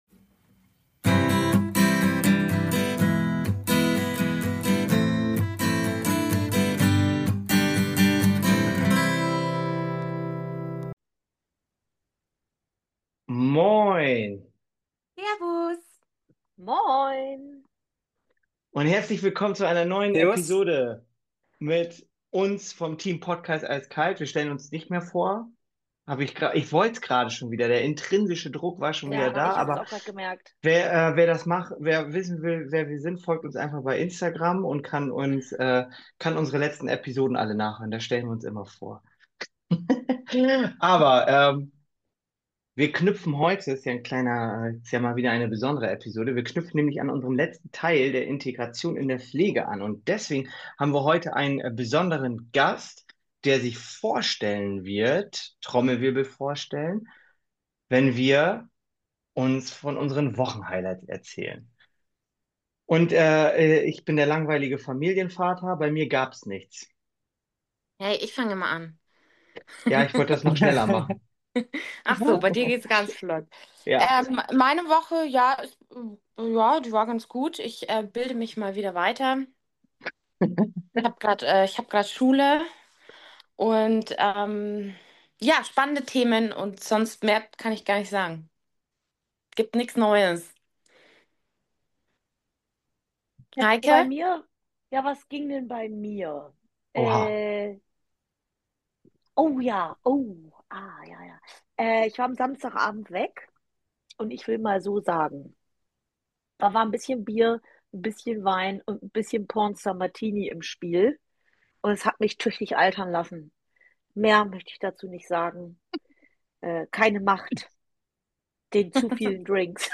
In dieser Episode sprechen wir mit unserem Gast über seinen Weg von Afghanistan nach Deutschland.